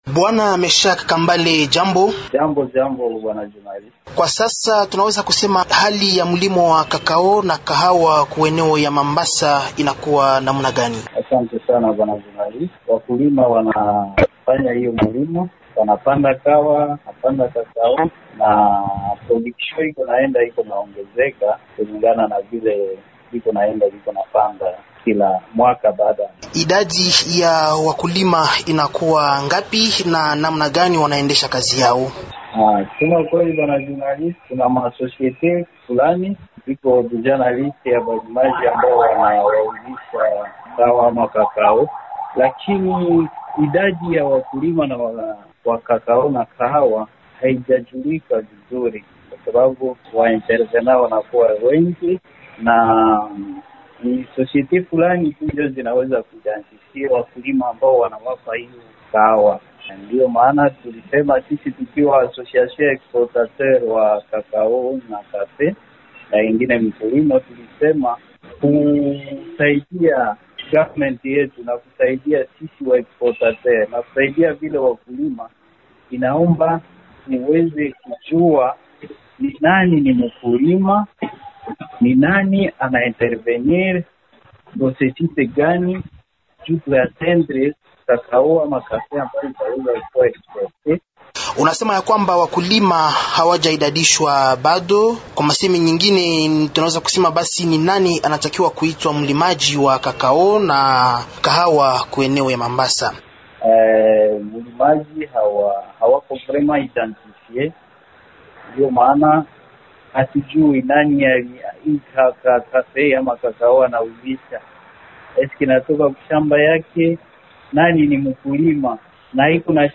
akiongea kwa simu na